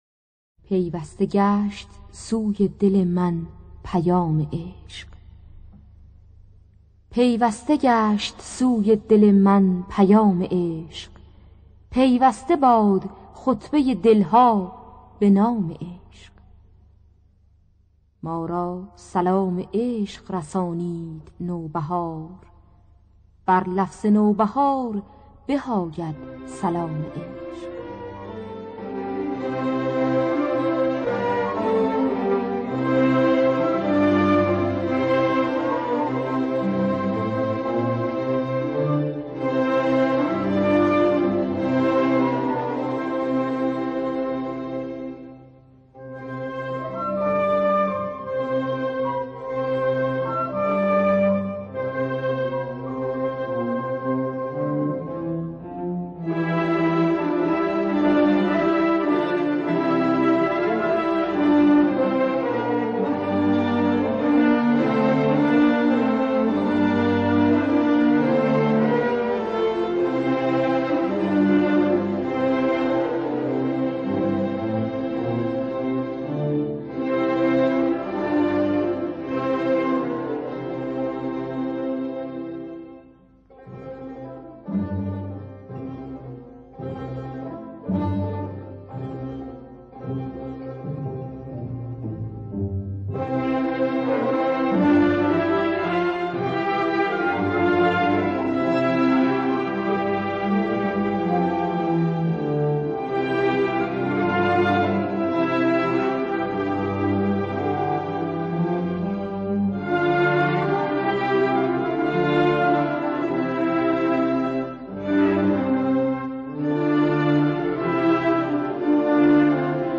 گلهای رنگارنگ ۳۱۸ - بیات ترک
خوانندگان: پروین حسین قوامی نوازندگان